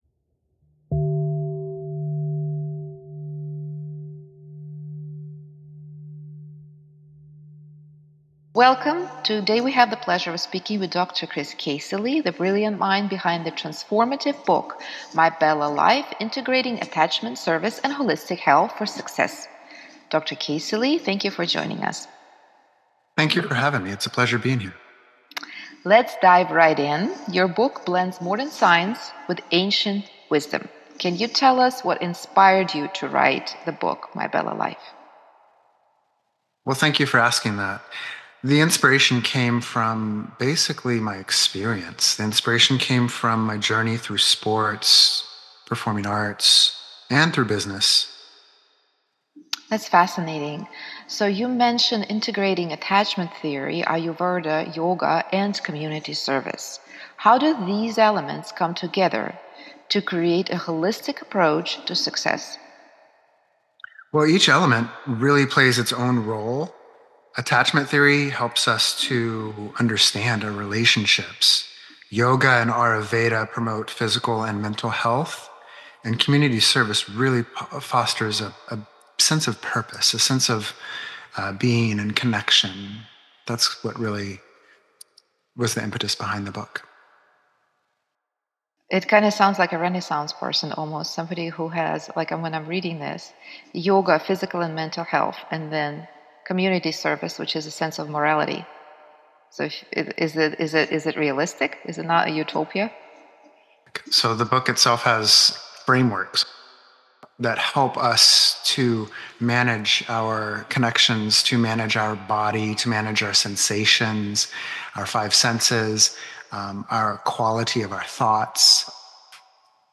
interview.mp3